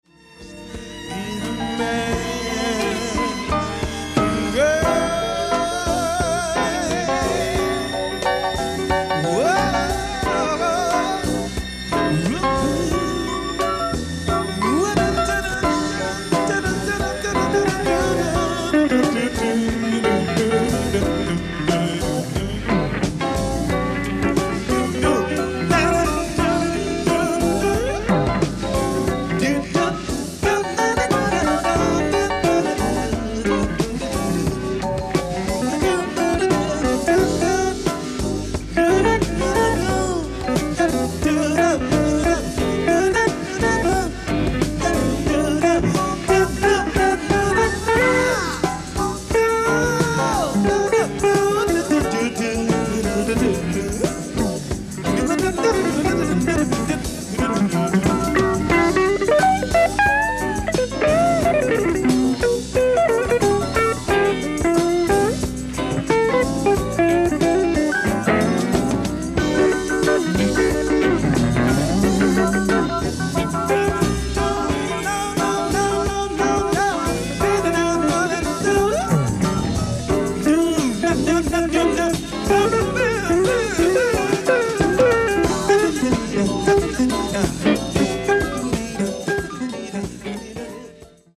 ディスク１：ライブ・アット・スペクトラム、フィラデルフィア 08/25/1979